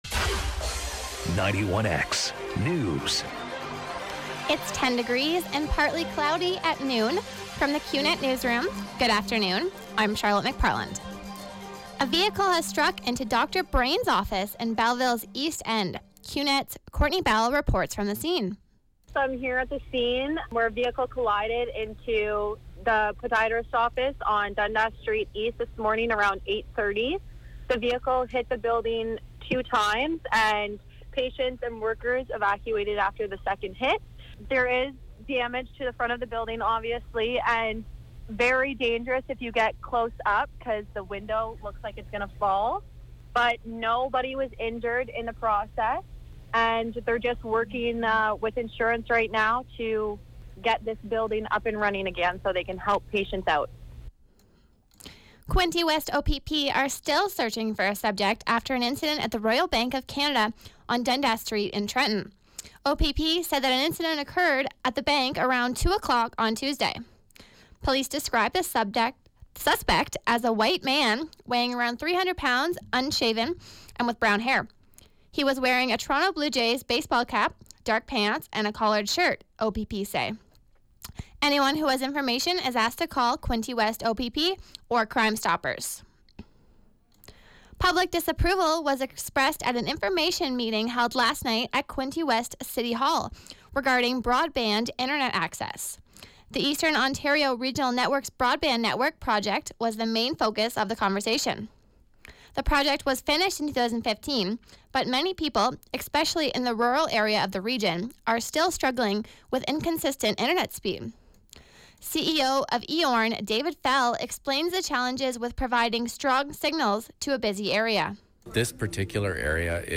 91X Newscast- Wednesday, Nov. 16, 2016, noon